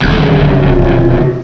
cry_not_gigalith.aif